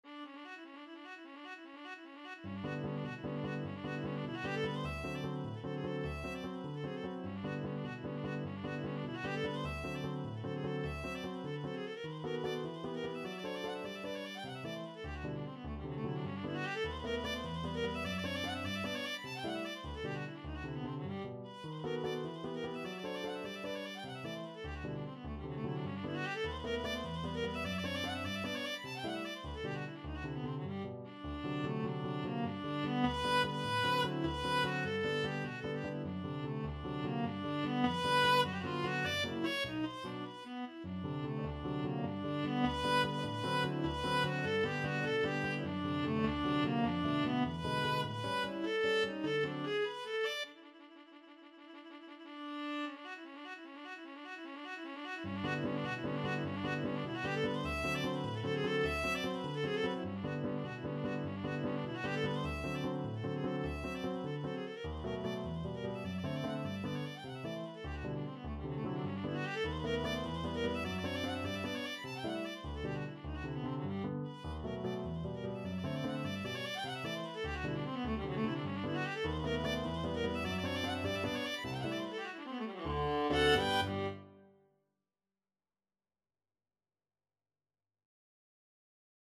ViolaPiano
3/4 (View more 3/4 Music)
Molto vivace .=100
Viola  (View more Advanced Viola Music)
Classical (View more Classical Viola Music)